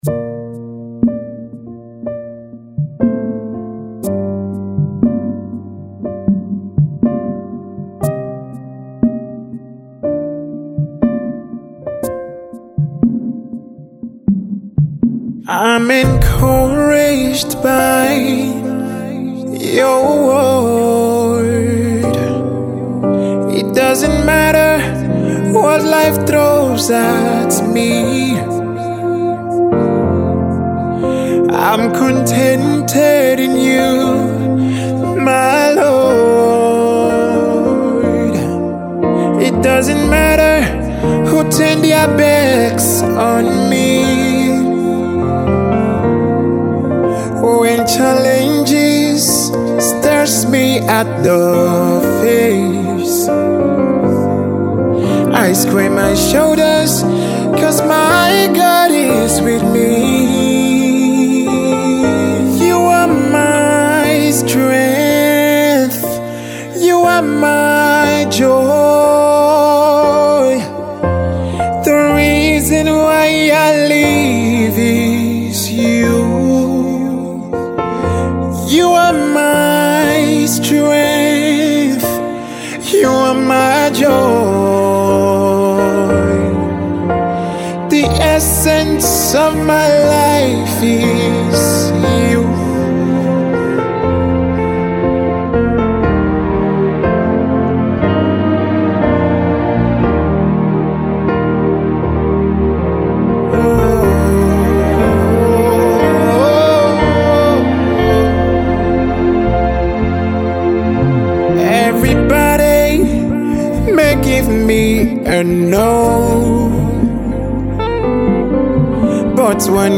Exceptional and award winning gospel artiste